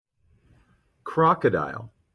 Crocodile 1
crocodile-1.mp3